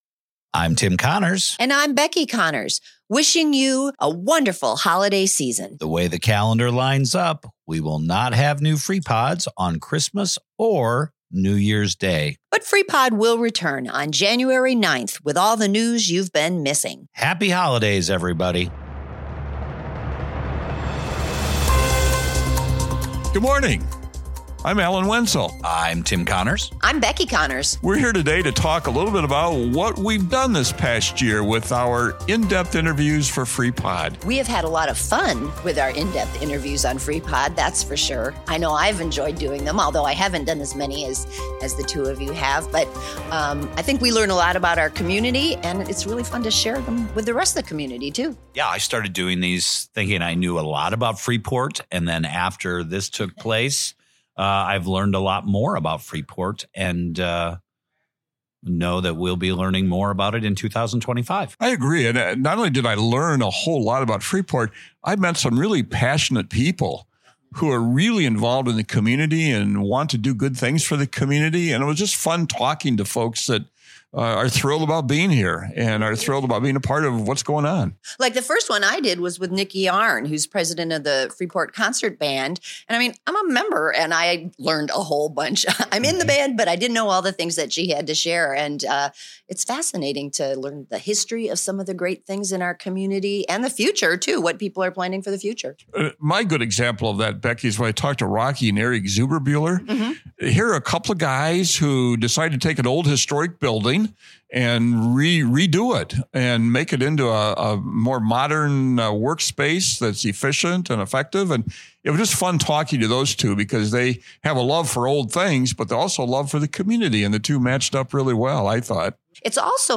Freepod - FREEPOD Interview: Year in Review